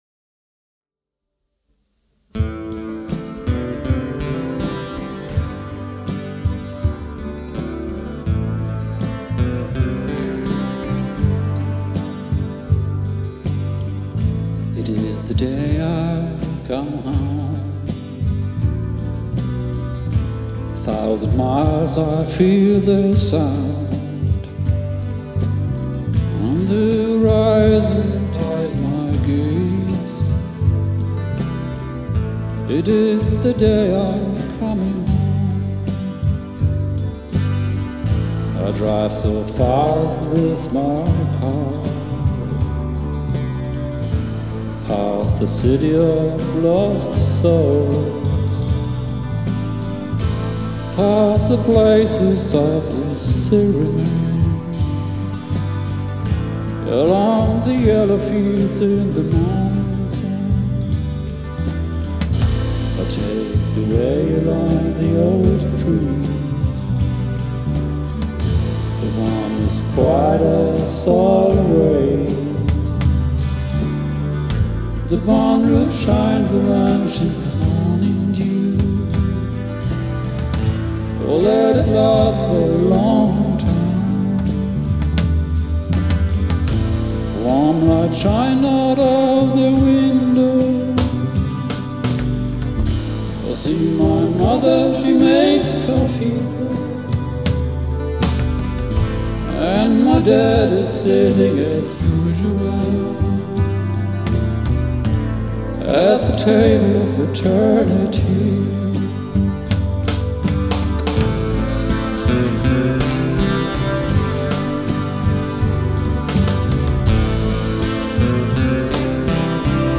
Die Aufnahmequalität ist nämlich nicht sonderlich gut. Aufgenommen mit einem einfachen Mikrofon zu Hause, sind die Tiefen und Nuancen über einfache Abspielgeräte, wie Handy oder Tablet, nicht sonderlich zu hören.
Einiges klingt auch schief, hatte dann aber keine Lust, alles noch einmal aufzunehmen.